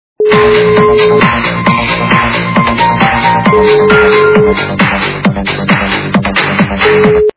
При прослушивании Для СМС - Техно-звонок качество понижено и присутствуют гудки.
Звук Для СМС - Техно-звонок